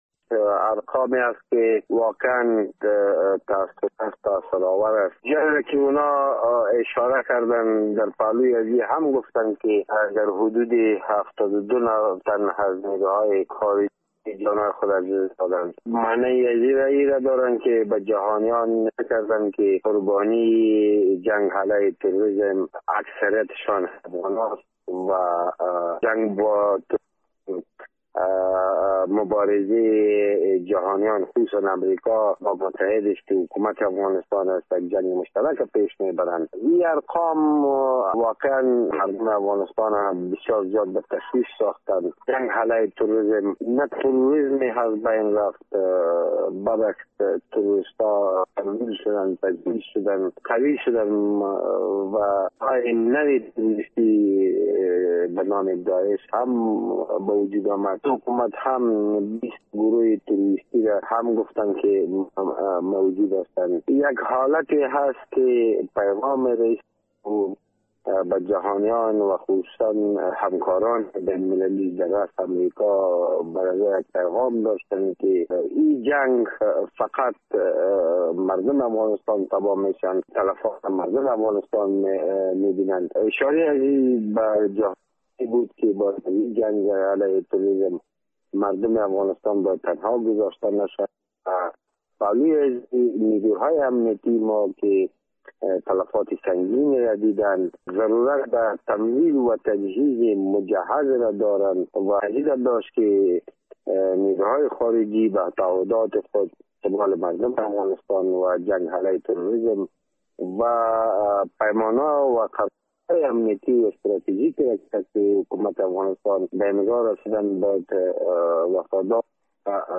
کارشناس سیاسی-نظامی افغان گفت:
در گفت و گو با خبرنگار رادیو دری